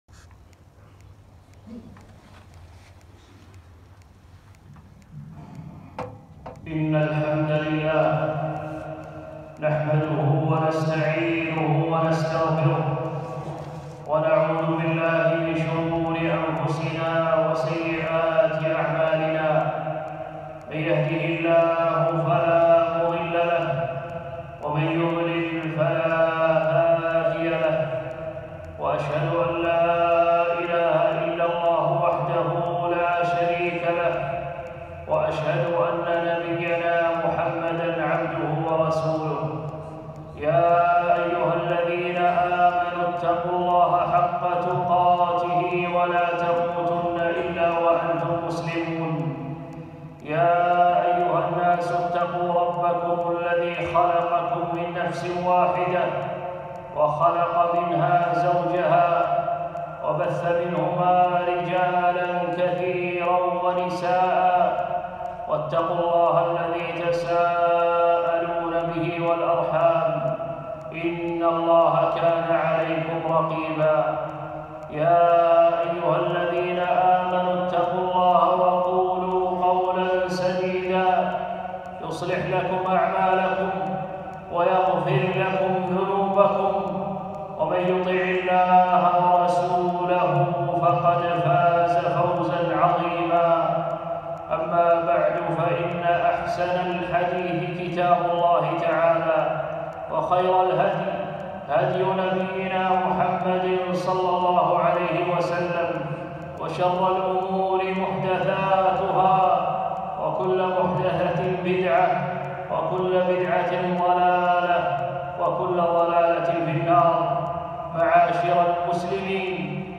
خطبة - المسجد الأقصى